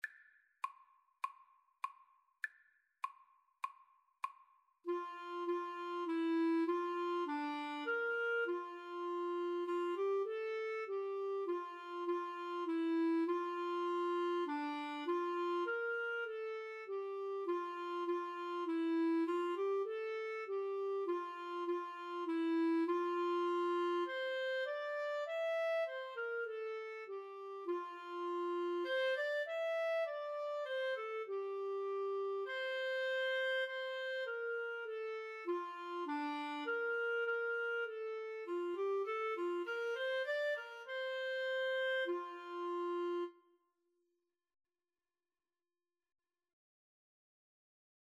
Free Sheet music for Clarinet Duet
4/4 (View more 4/4 Music)
F major (Sounding Pitch) G major (Clarinet in Bb) (View more F major Music for Clarinet Duet )